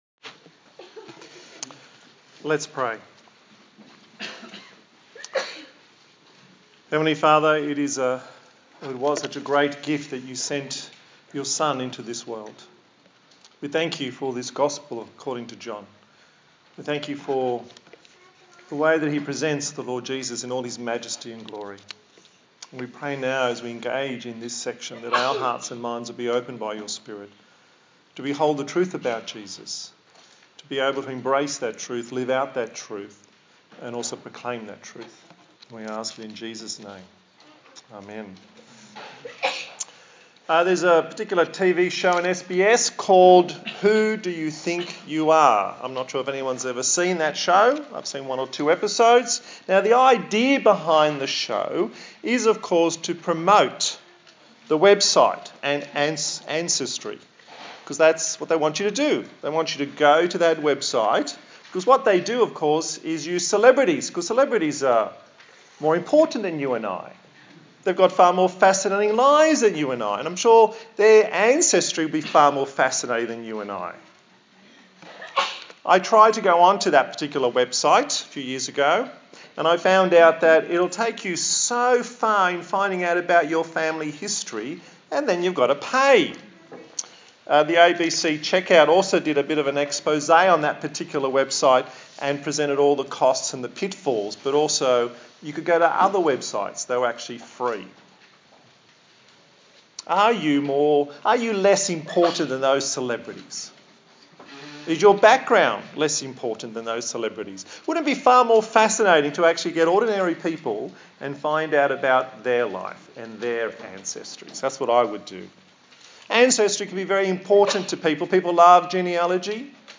A sermon in the series on the book of John
Service Type: Sunday Morning